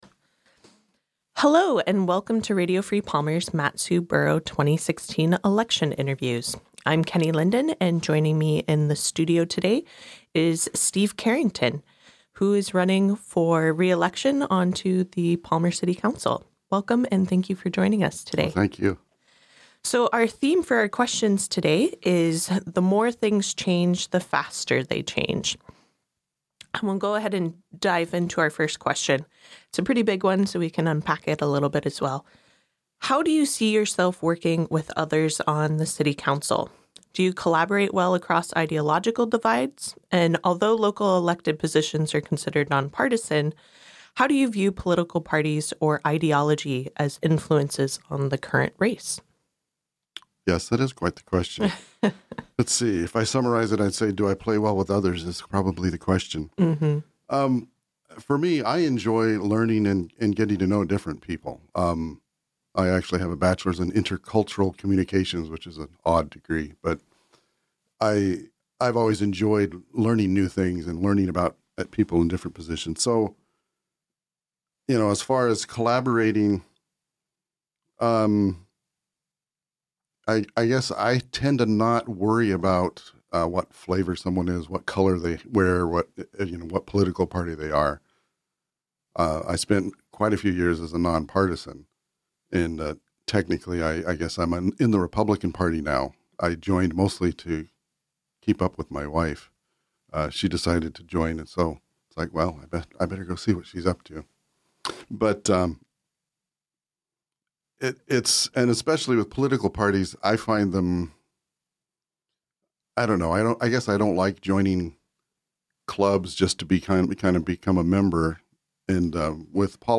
Palmer City Council 2016 Candidate Interviews